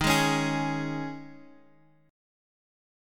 D#7sus4 chord